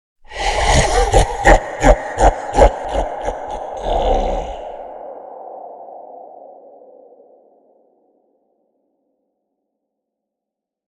monster-laugh
bad cackle comedy evil giant horror humor insane sound effect free sound royalty free Funny